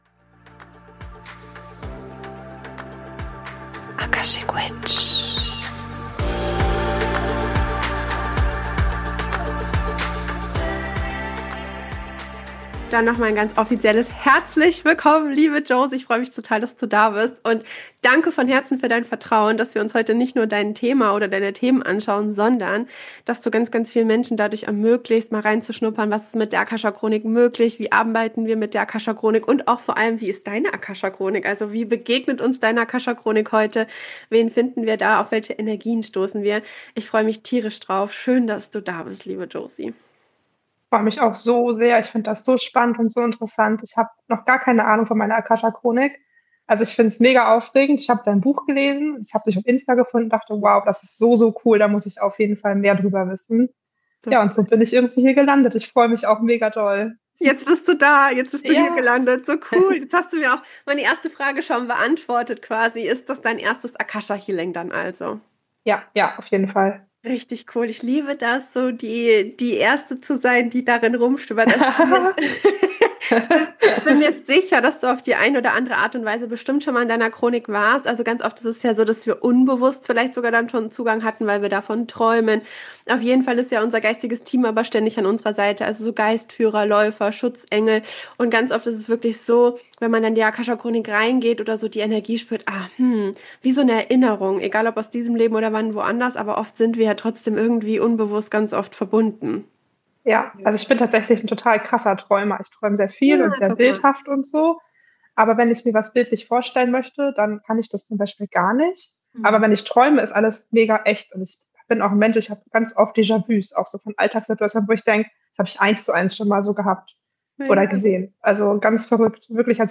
Live-Akasha-Chronik-Reading & Healing